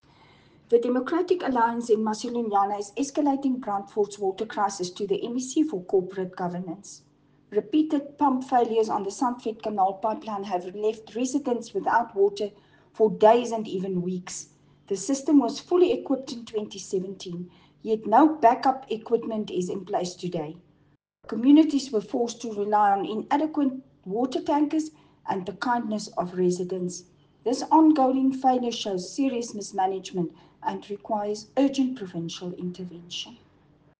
Afrikaans soundbites by Cllr Marieta Visser and